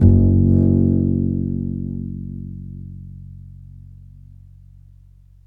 DBL BASS G#1.wav